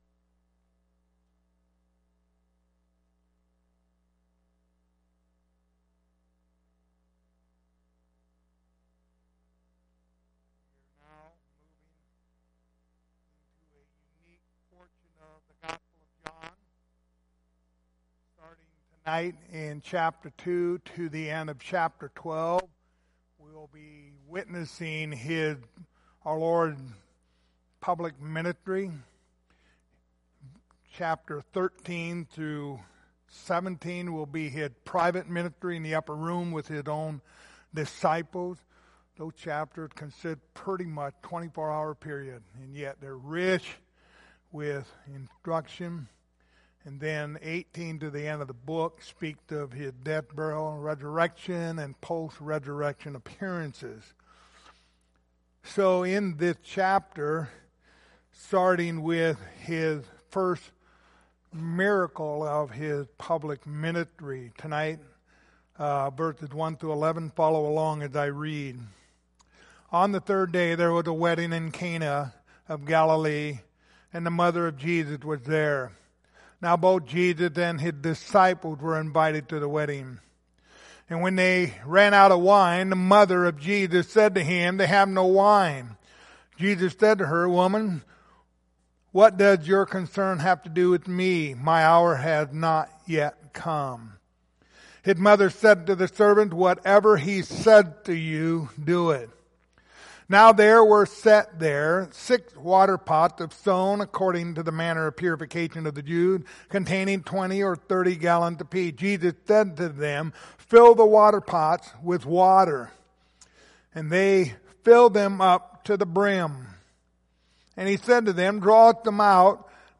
The Gospel of John Passage: John 2:1-11 Service Type: Wednesday Evening Topics